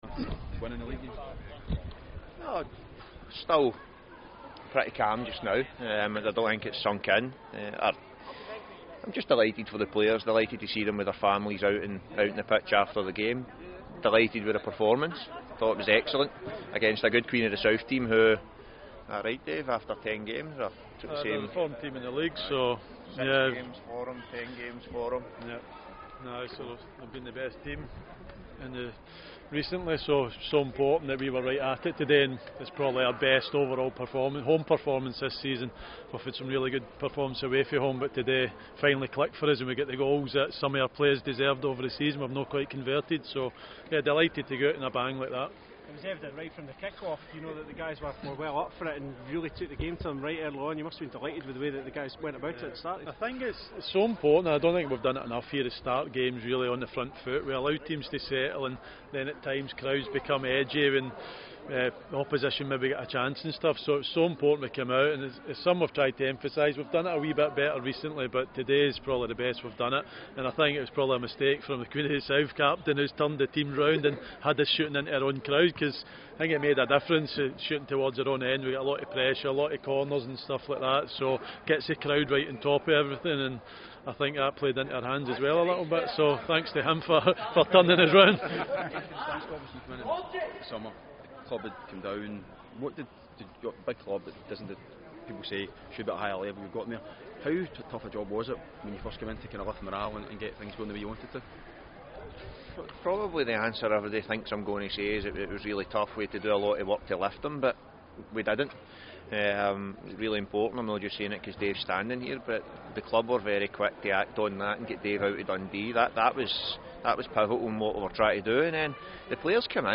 Listen to this management team interview